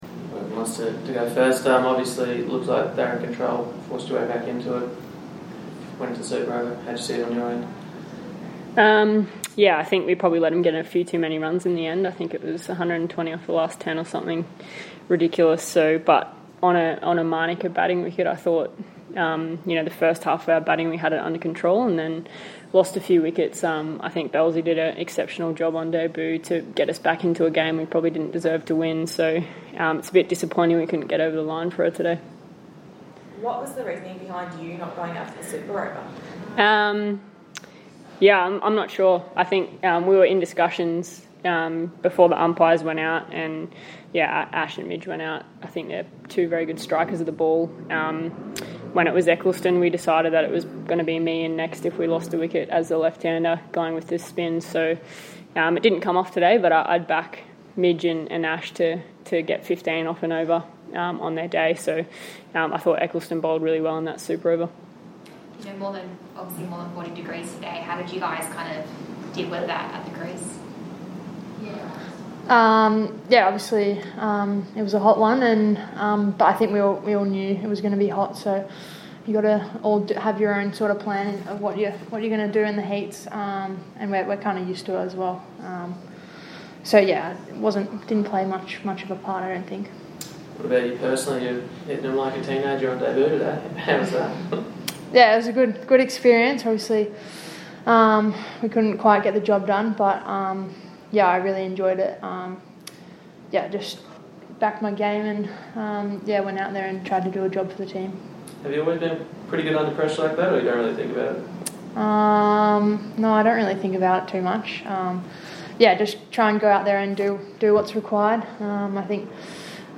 Beth Mooney and Annabel Sutherland Speak After Loss